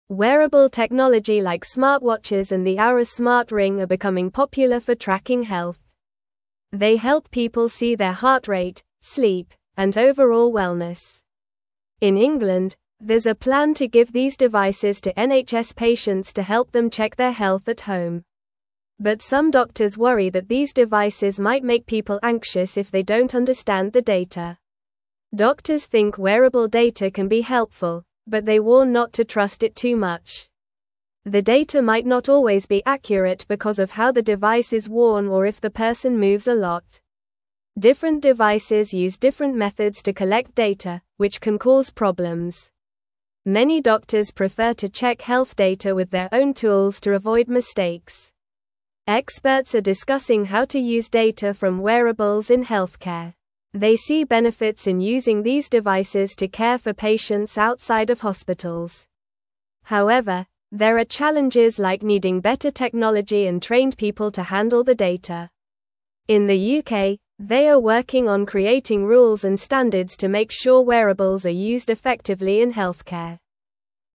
Normal
English-Newsroom-Lower-Intermediate-NORMAL-Reading-Smart-Devices-Help-Check-Health-at-Home.mp3